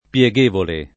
[ p L e g% vole ]